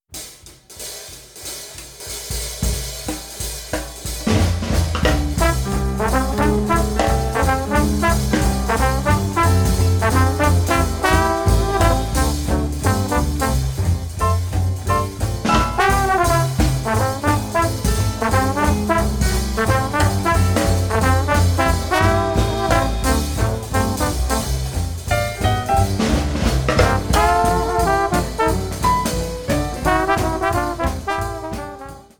trumpet, trombone, alto sax, tenor sax
tenor sax, alto sax, clarinet, normaphone
piano
guitar, vocals
drums